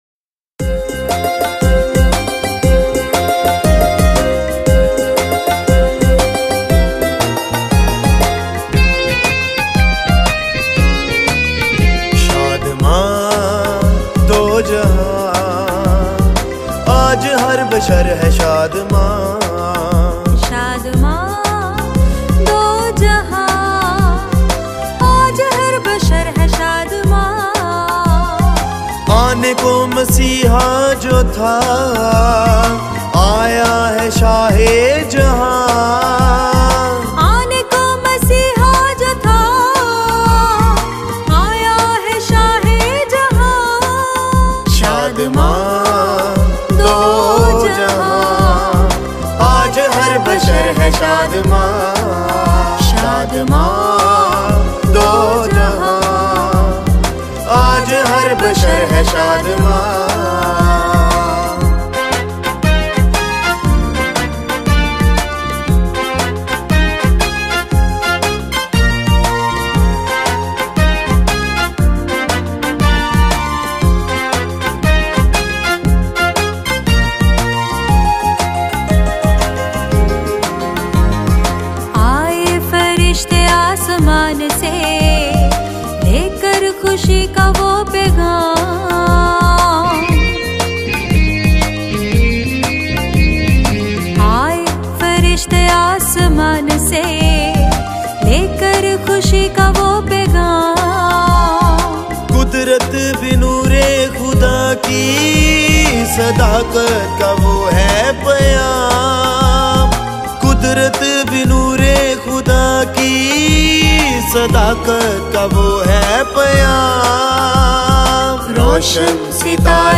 Listen and download Gospel songs